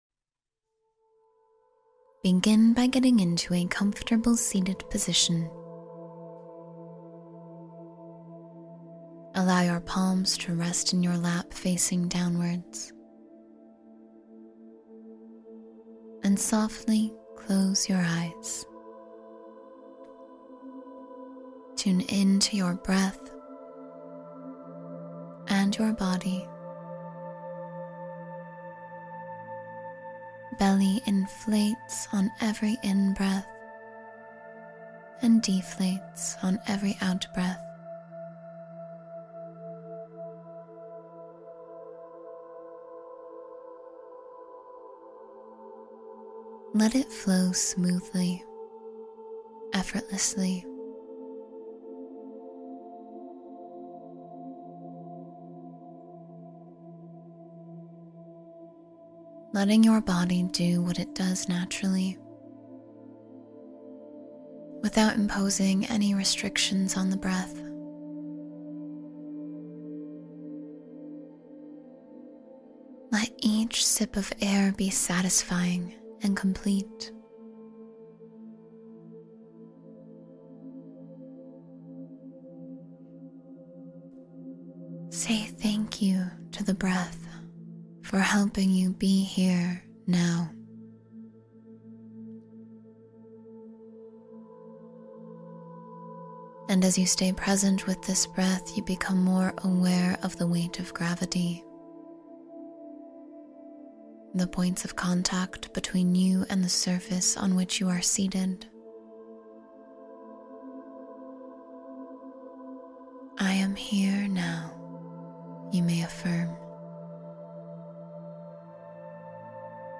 Winter Solstice Energy Meditation — Connect with Seasonal Renewal